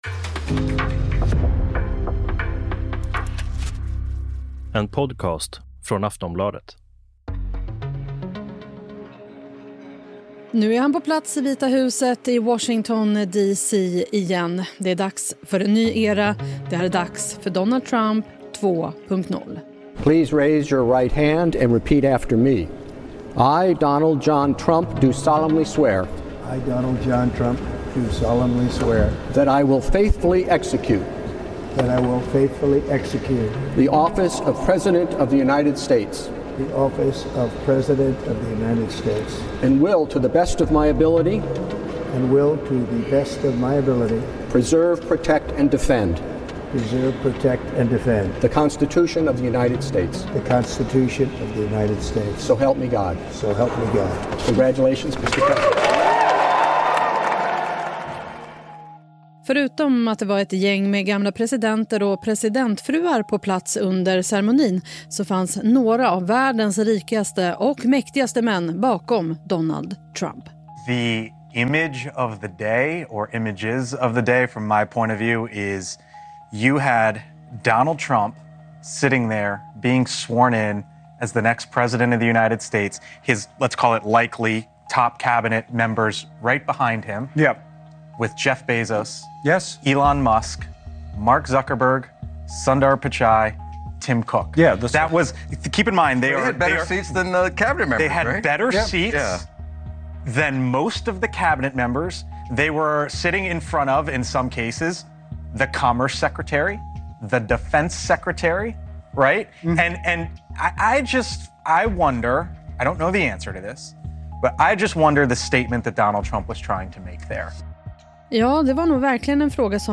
Klipp från: Aftonbladet, News Nation.